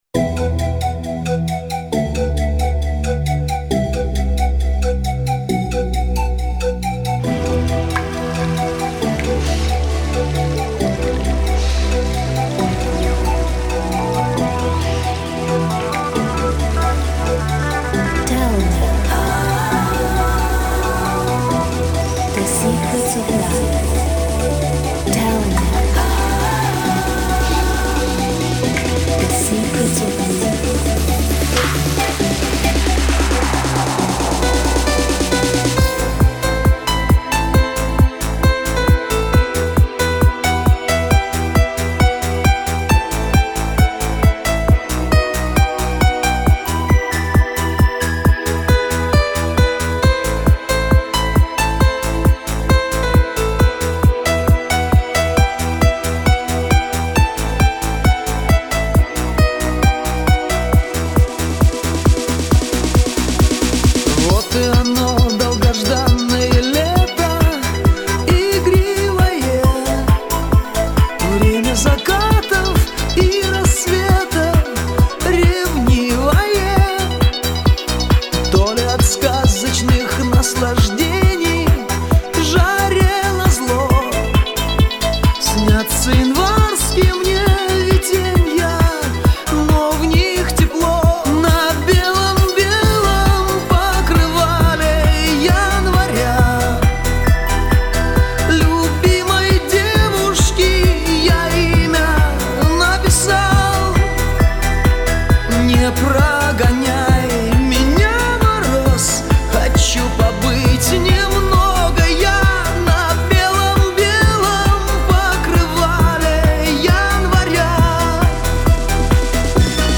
это трогательная песня в жанре поп-музыки